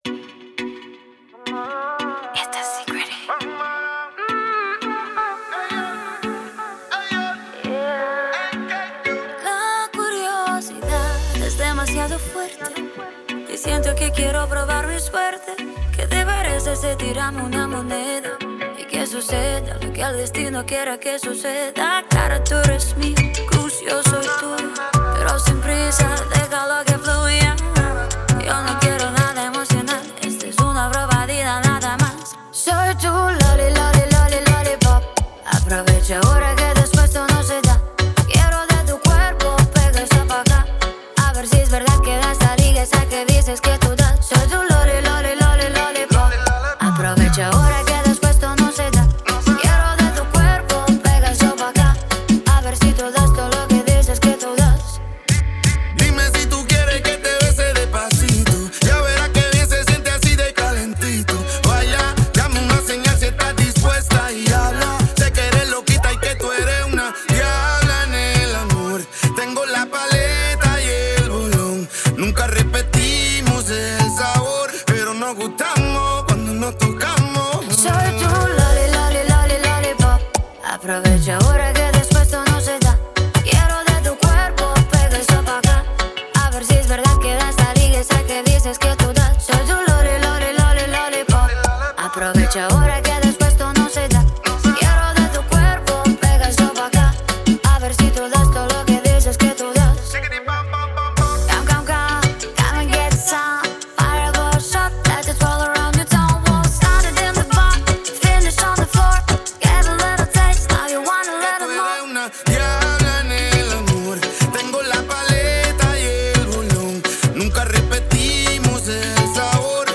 Латиноамериканская